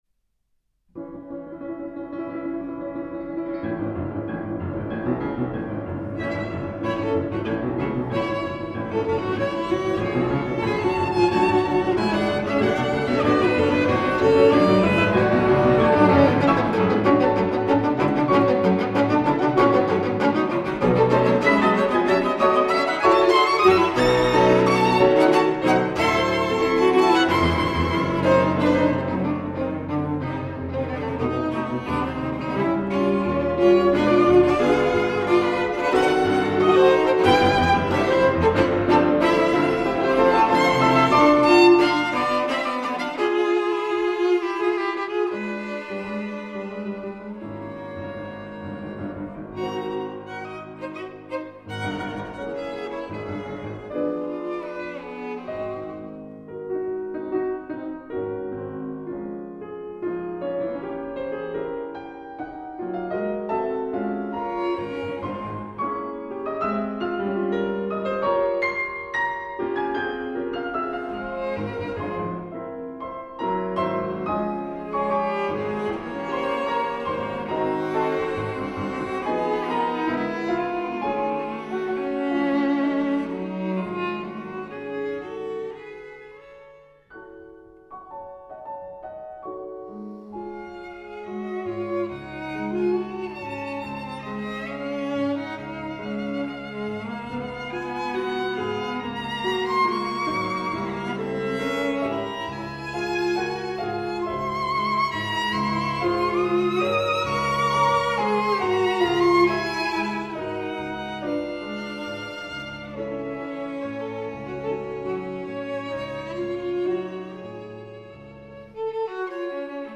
Amy Mary Beach - Klaviertrio in a-Moll, 3. Satz
Amy-Mary-Beach-Piano-Trio-A-minor-III.mp3